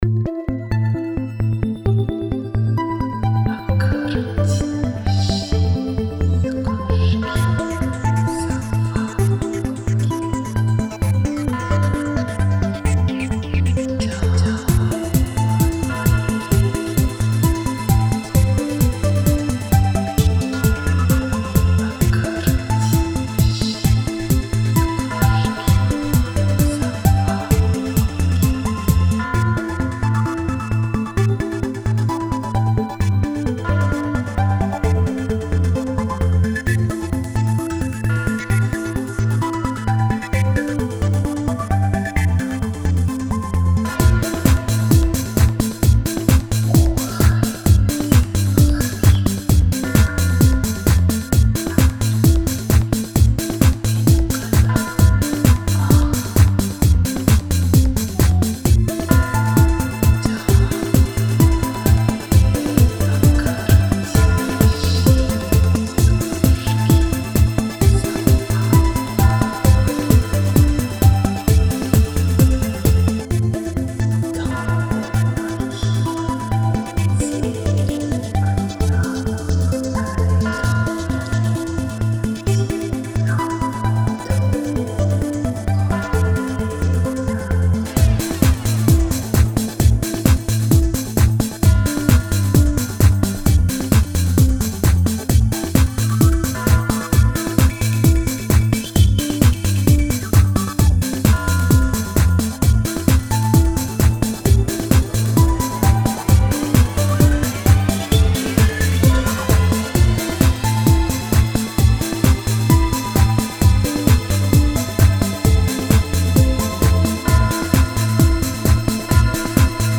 Electronic and Ambient